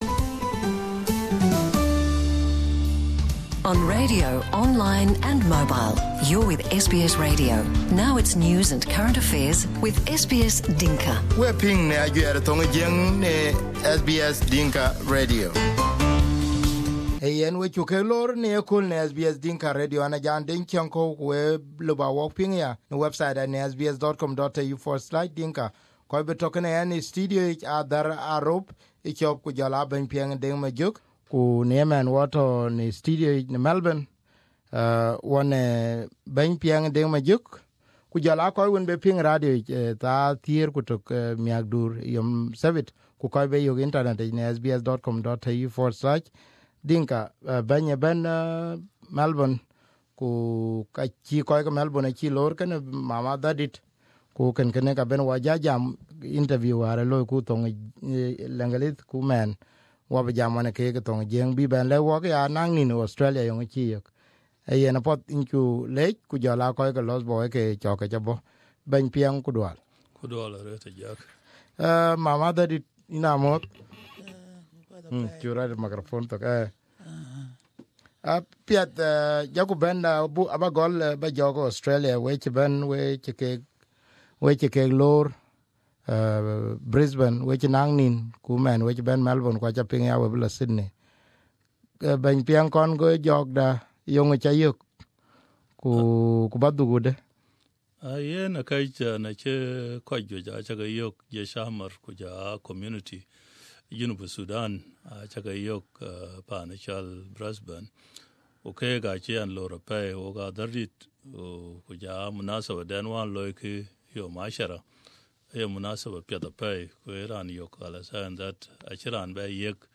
in SBS studio